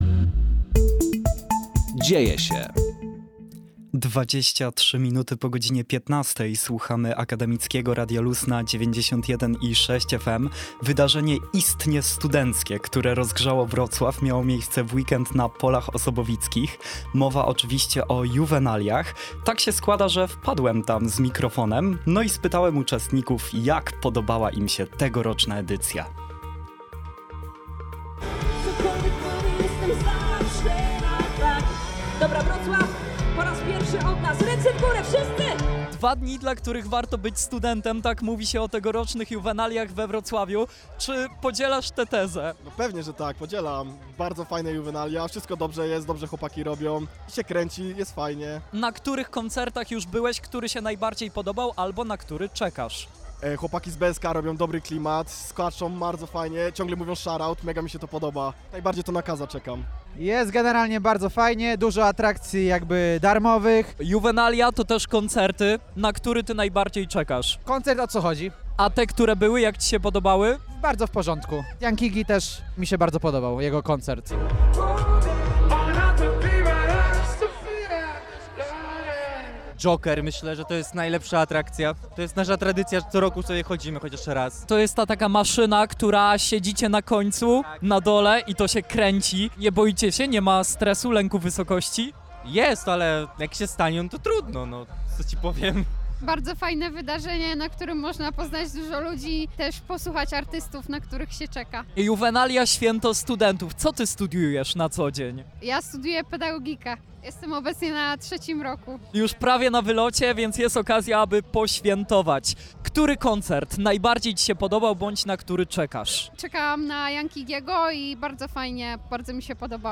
Przygotowaliśmy relację z tego wydarzenia – zarówno dla tych, którzy nie mieli okazji się tam pojawić, jak i tych, którzy chcieliby przeżyć to jeszcze raz.